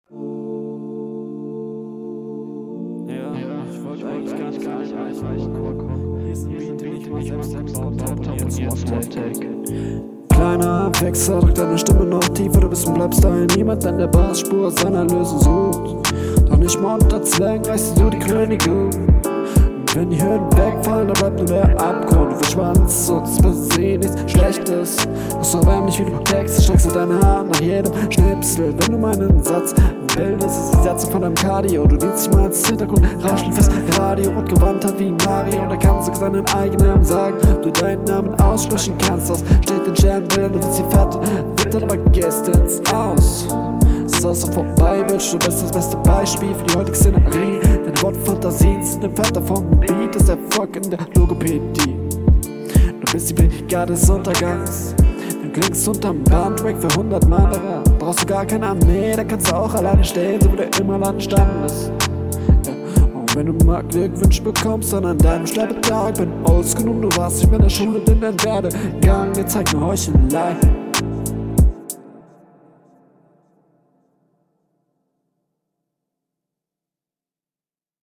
Klingt noch bisschen unroutiniert. Musst mal bisschen am Stimmeinsatz arbeiten. Mix ist ganz gut.